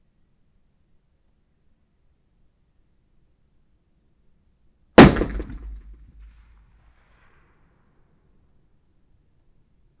01_gunshot/shot556_164_ch01_180718_164323_76_.wav · UrbanSounds/UrbanSoundsNew at main
Environmental
Streetsounds
Noisepollution